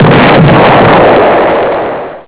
69                           <!-- The explosion rings are based on the effects of approximately 1/3 ton of TNT. -->